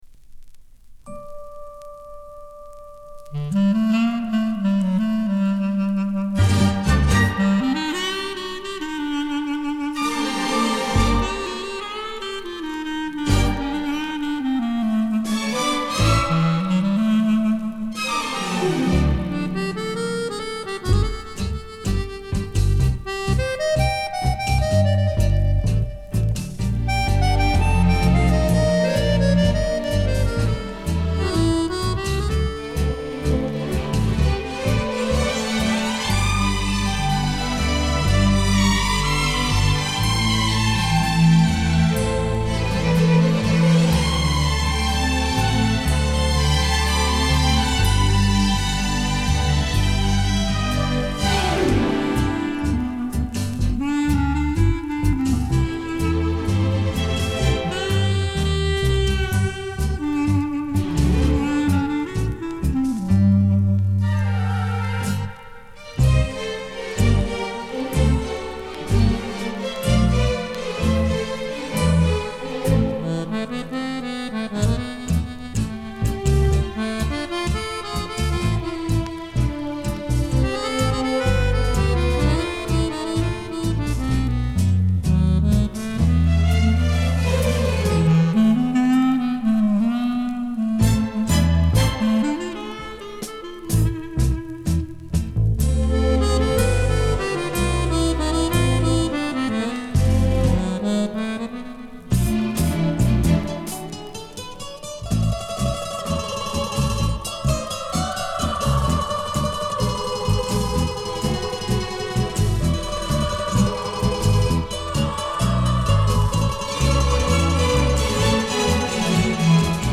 Genre:Latin
Style:Tango, Bolero